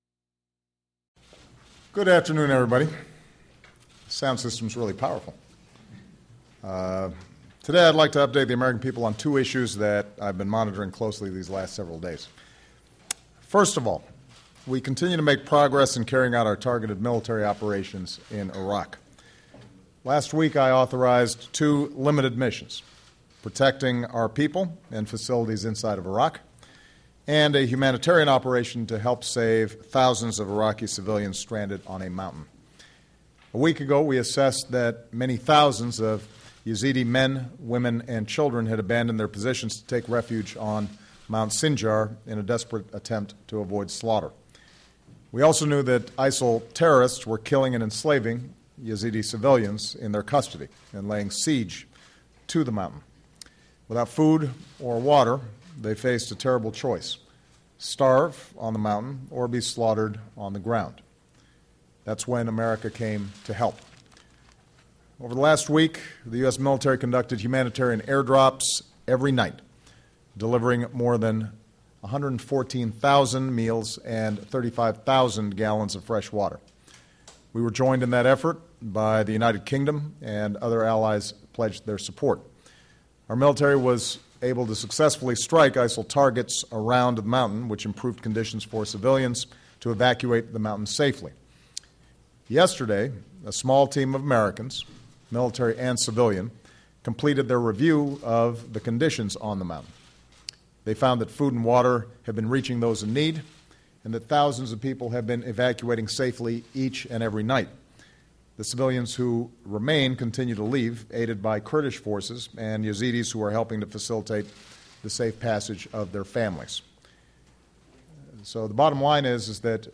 U.S. President Barack Obama makes a statement on the latest developments in Iraq and the killing of an unarmed African American by police in Ferguson, MO